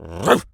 dog_large_bark_01.wav